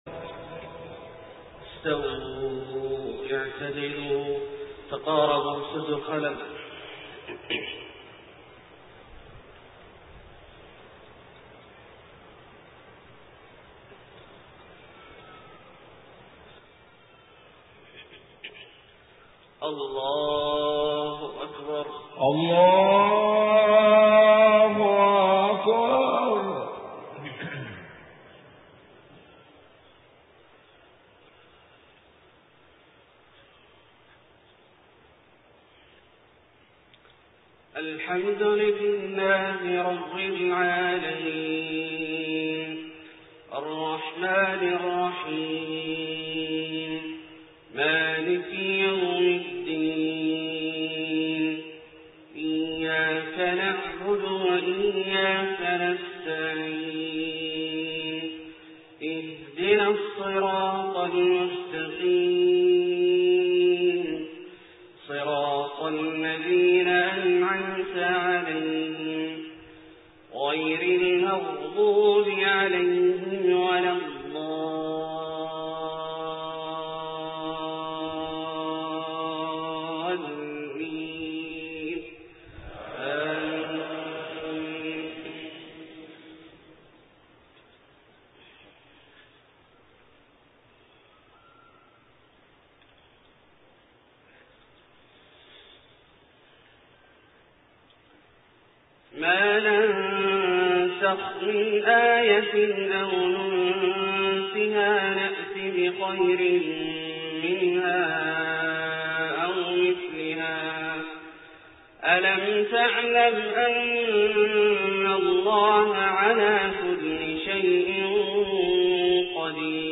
fajr prayer 8-8-2007 from surah AlBaqarah > 1428 H > Prayers - Abdullah Al-Juhani Recitations